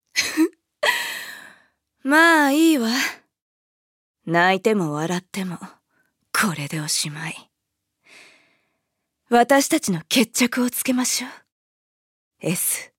음성 대사